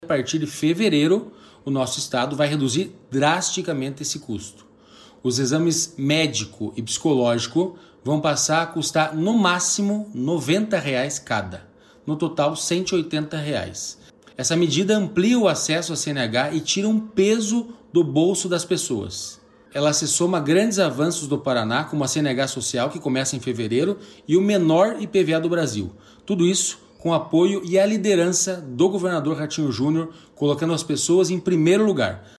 Sonora do diretor-presidente do Detran-PR, Santin Roveda, sobre o projeto de lei para reduzir em 55% o custo dos exames da CNH no Paraná